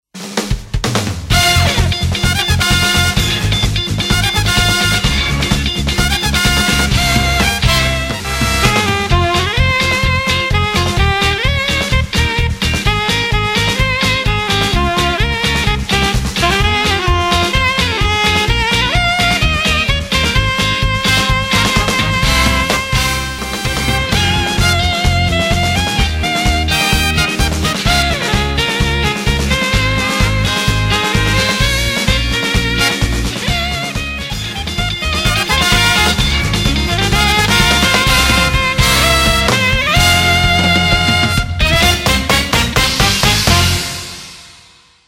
難易度 分類 並足128 時間 2分４1秒
編成内容 大太鼓、中太鼓、小太鼓、シンバル 作成No ２６５